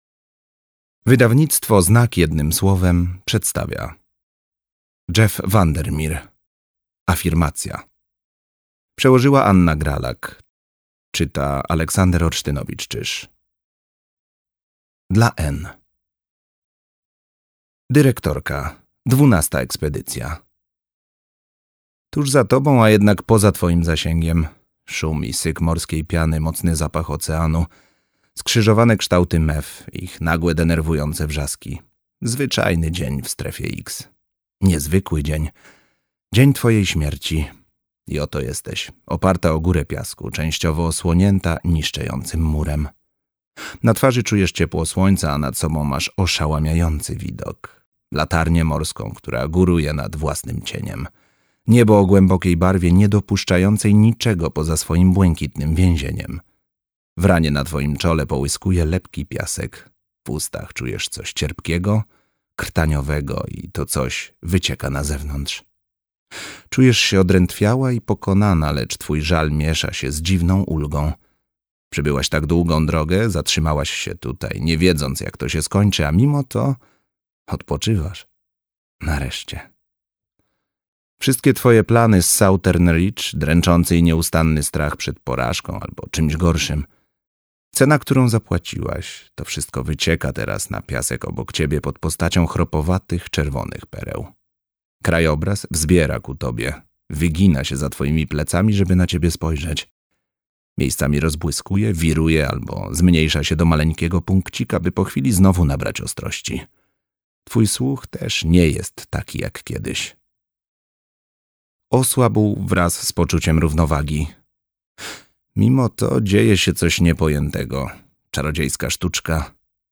Afirmacja - Jeff VanderMeer - audiobook